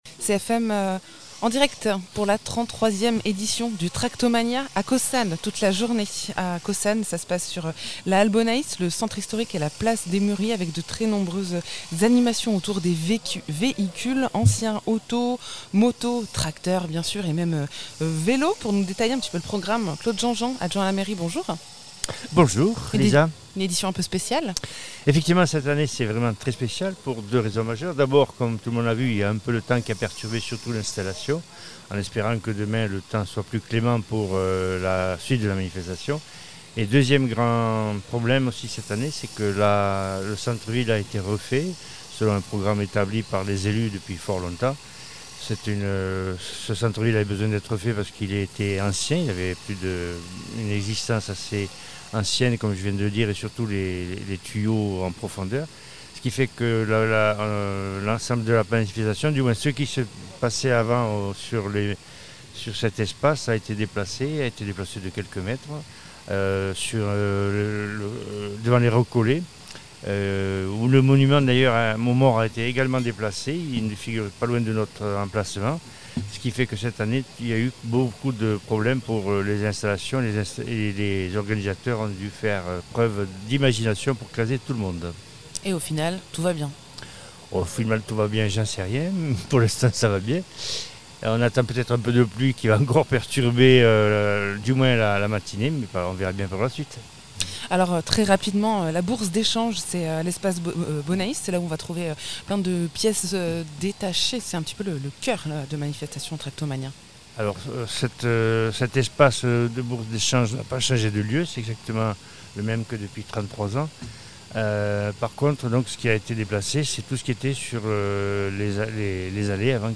On était sur place, à Caussade, pour vous faire vivre avec nous la 33ème édition de la Tractomania en jaune et vert : les tracteurs John Deere était à l’honneur ce samedi 19 et ce dimanche 20 Octobre. Les machines à vapeur, les tracteurs, autos, motos (et même vélos ! ) anciens, les moteurs fixes et autres locomobiles, expositions, bourses d’échange et animations leur tenaient compagnie.
Interviews